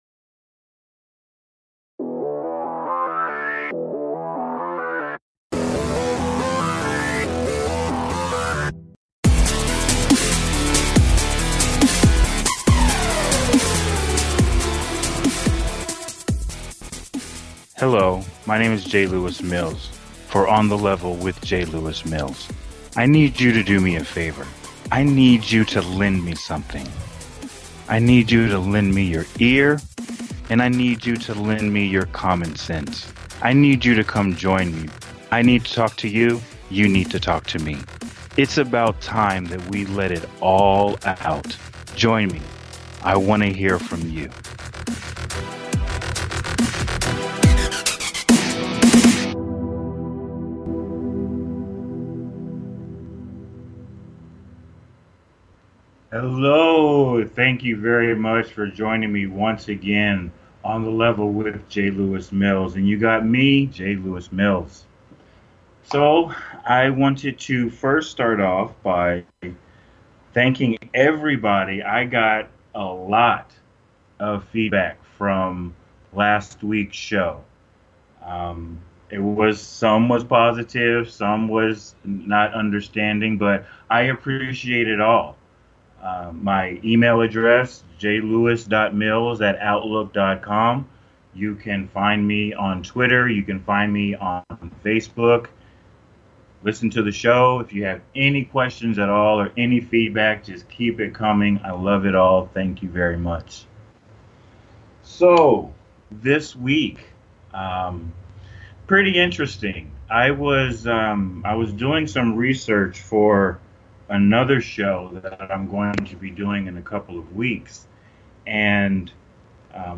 Talk Show Episode
with callers welcome to either join the debate or throw your Q’s into the pot!